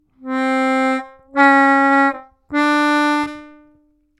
отрывисто в обратную сторону +1-1′ -1